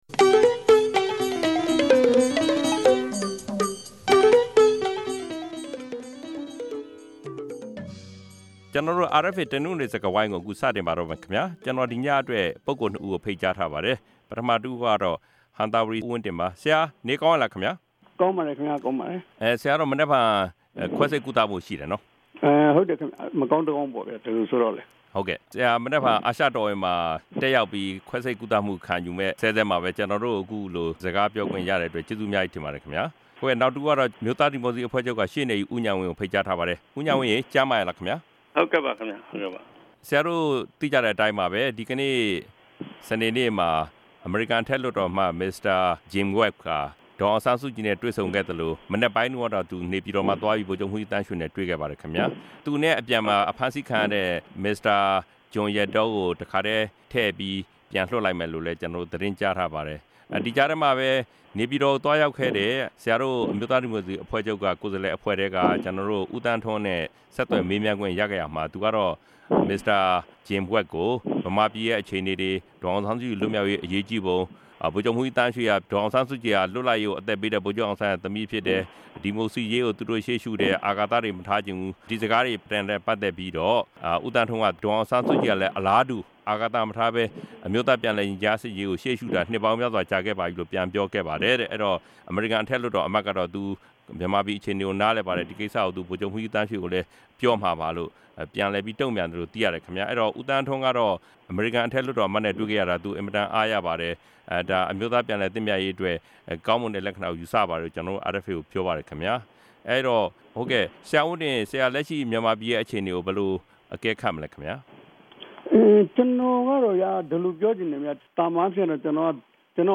ပၝဝင်ဆြေးေိံြးထားပၝတယ်။